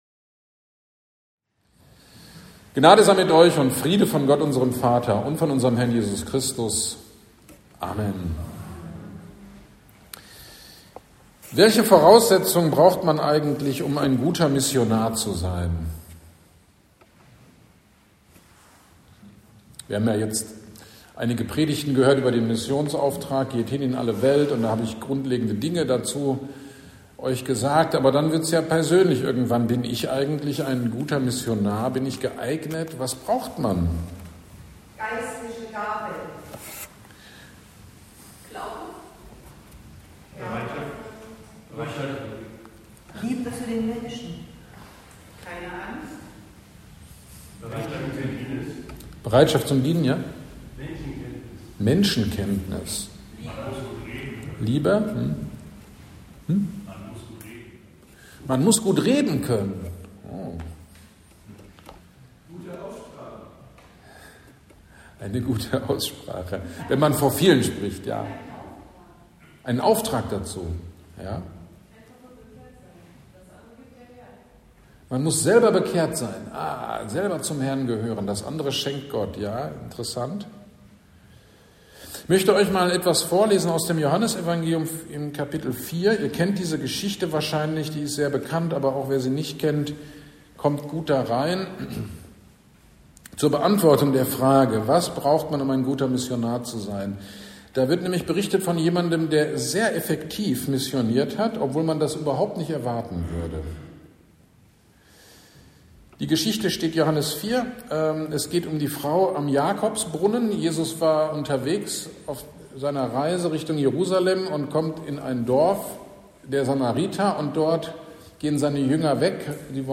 GD am 26.10.25 Predigttext: Johannes 4, 19-42 Thema Mission